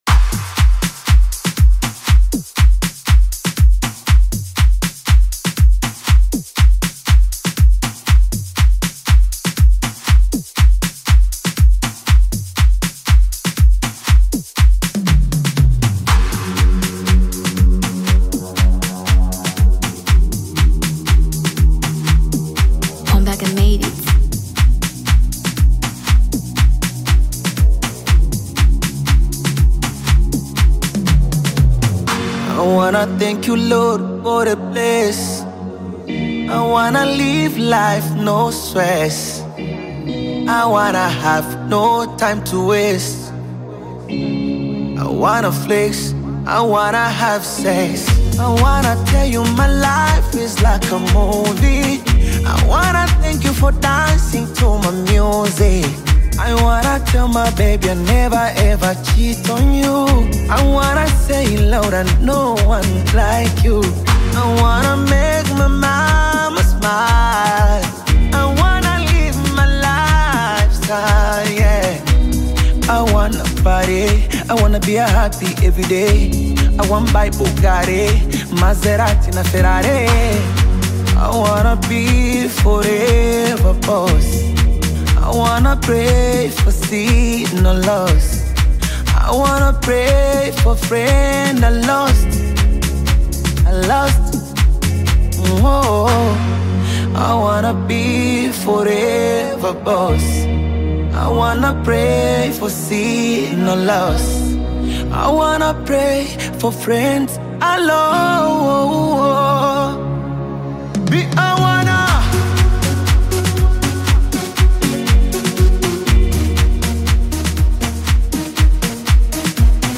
amapiano music